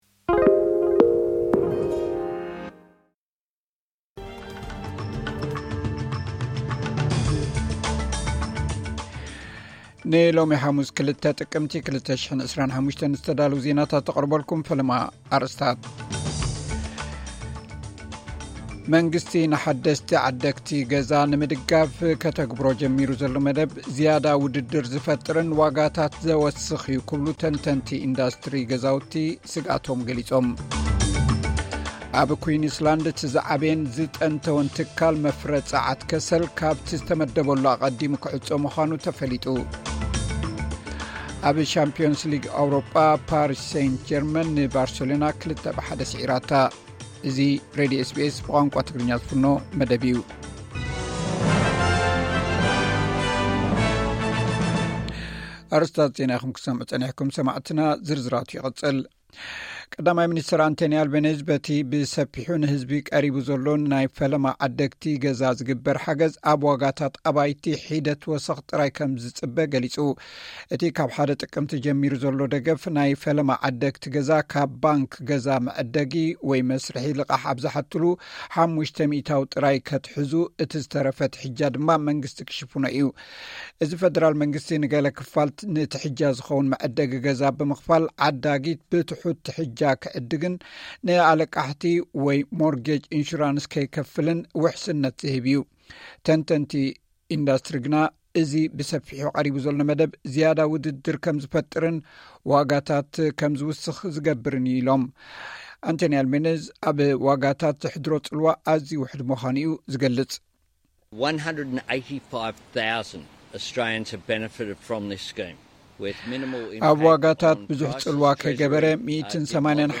ዕለታዊ ዜና ኤስ ቢ ኤስ ትግርኛ (02 ጥቅምቲ 2025)